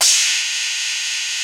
CYM XCHEEZ0C.wav